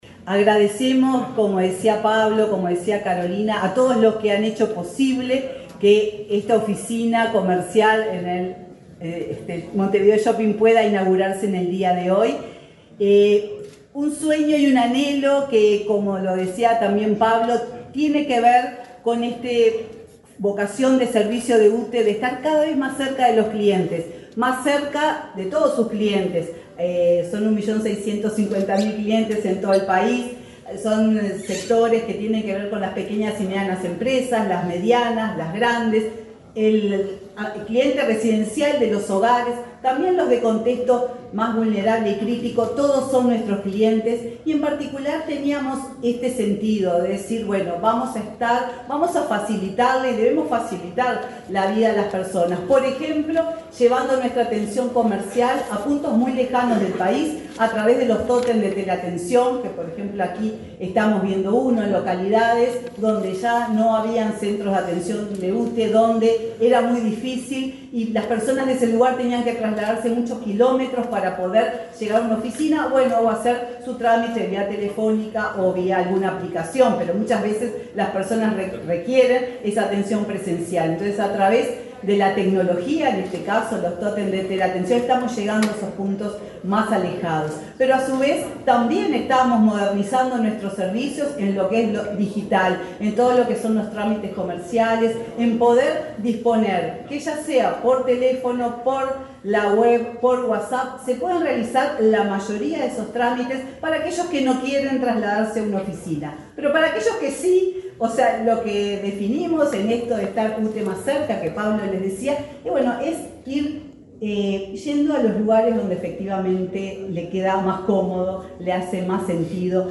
Palabras de la presidenta de UTE, Silvia Emaldi
Este viernes 26, la presidenta de la UTE, Silvia Emaldi, participó en la inauguración de una oficina comercial del organismo en el Montevideo Shopping